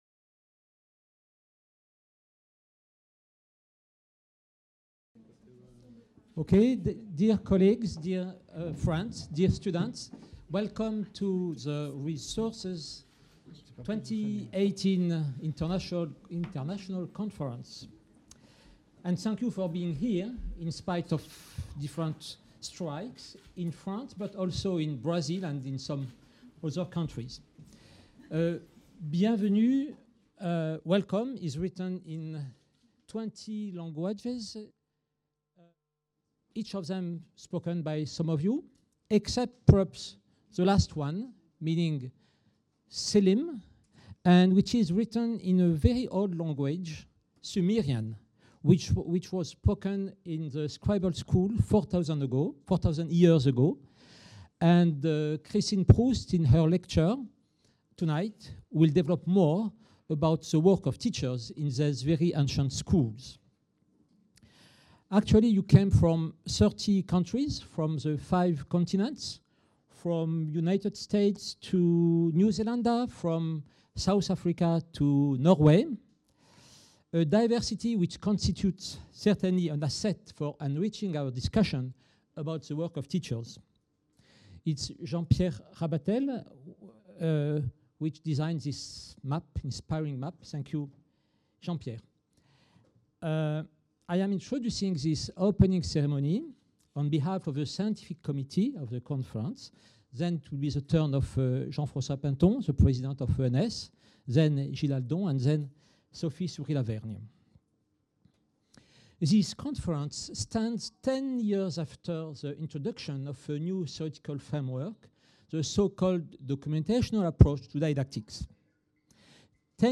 Opening Ceremony | Canal U